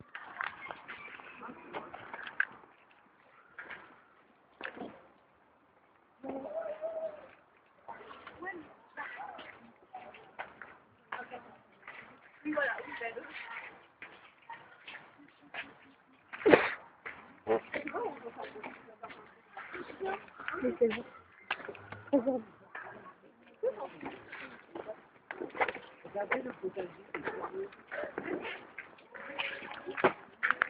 Jardin de l'évéchet (2)
oiseaux